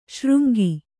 ♪ śřngi